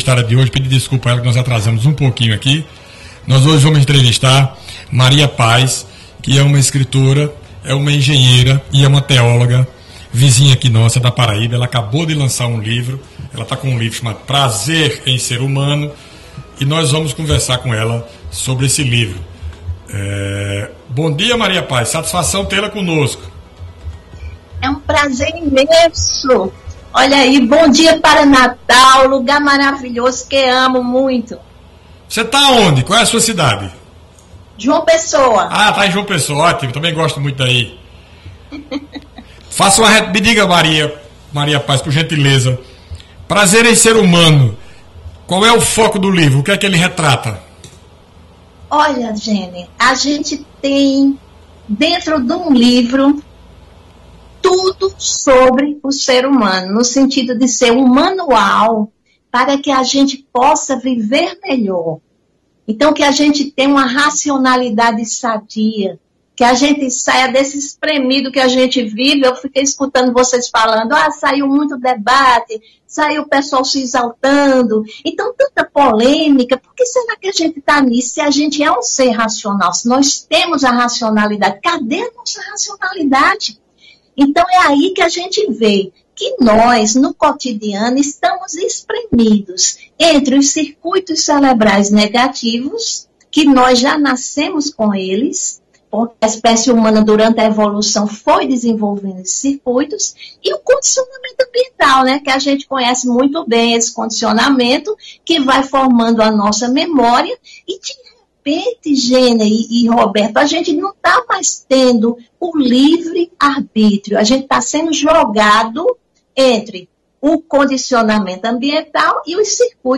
A entrevista foi realizada ao vivo no dia 26 de janeiro de 2022, para o programa Bom Dia CBN